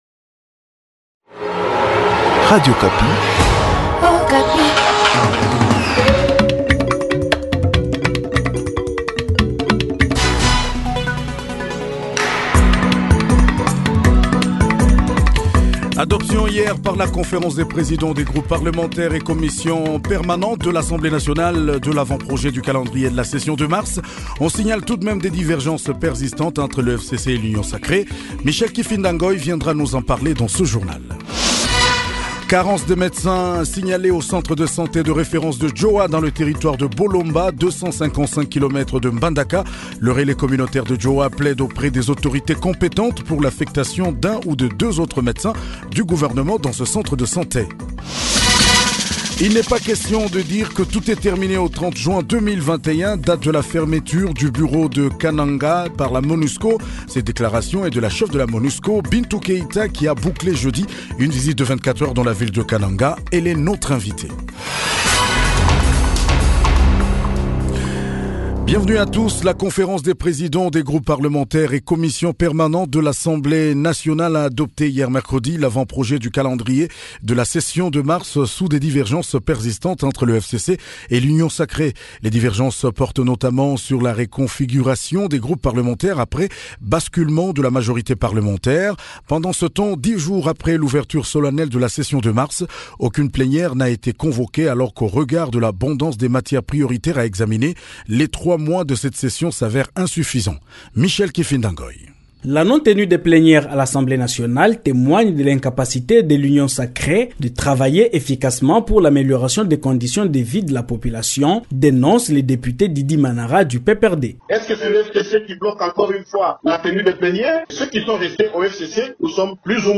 JOURNAL SOIR DU 25 MARS 2021